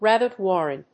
rábbit wàrren
音節rábbit wàrren